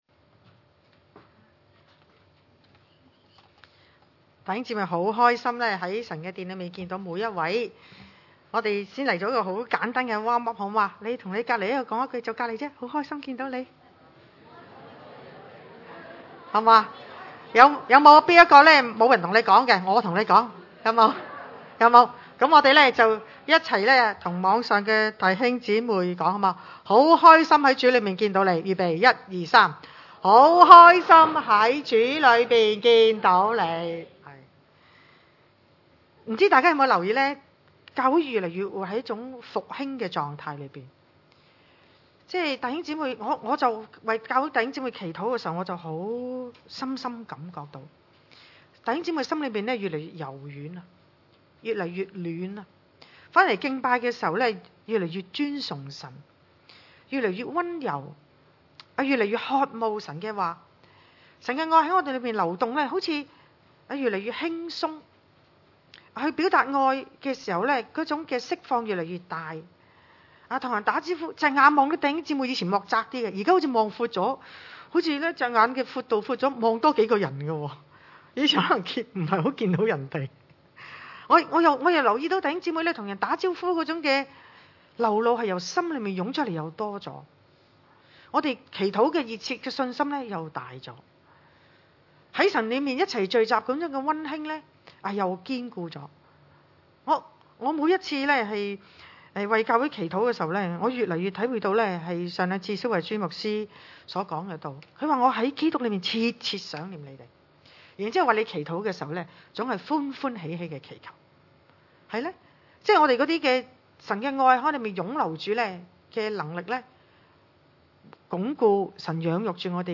約翰一書第4章 崇拜類別: 主日午堂崇拜 親愛的弟兄啊，一切的靈，你們不可都信，總要試驗那些靈是出於神的不是，因為世上有許多假先知已經出來了。